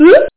UGH.mp3